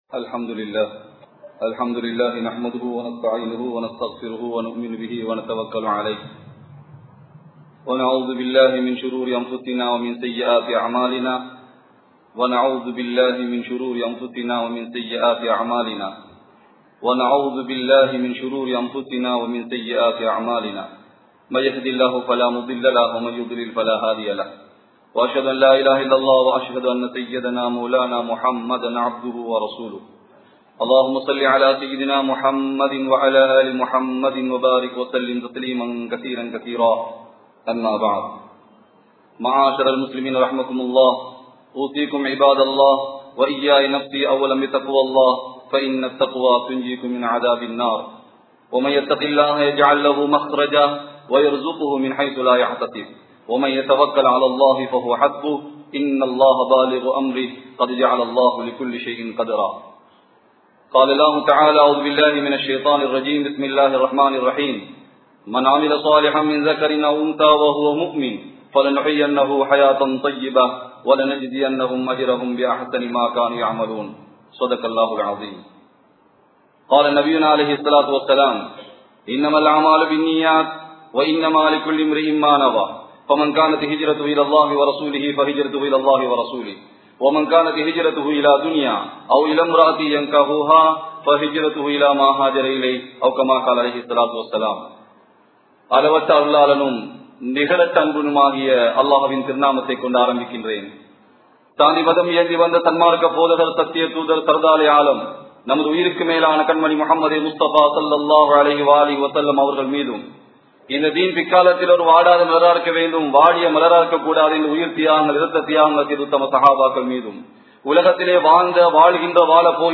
Nabiyin Sunnathukkalaip Pinpatruvathin Avasiyam(நபியின் ஸுன்னத்துக்களைப் பின்பற்றுவதின் அவசியம்) | Audio Bayans | All Ceylon Muslim Youth Community | Addalaichenai
Samman Kottu Jumua Masjith (Red Masjith)